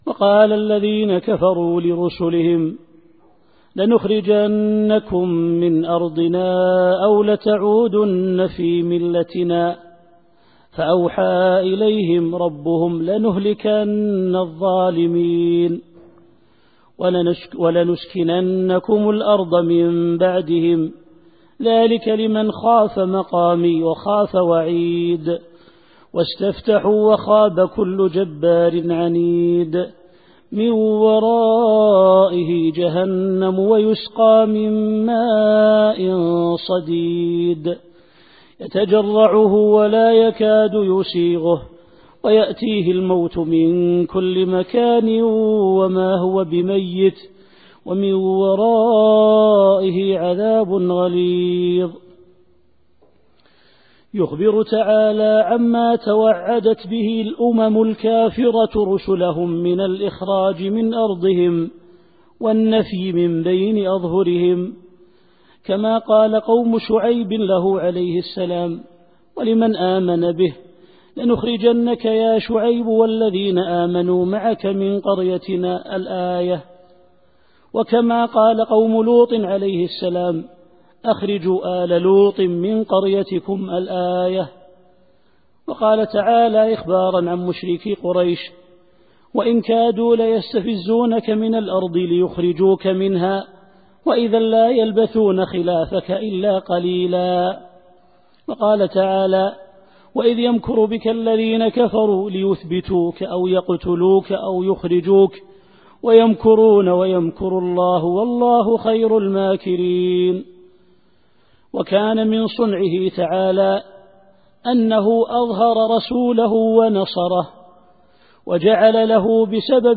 التفسير الصوتي [إبراهيم / 13]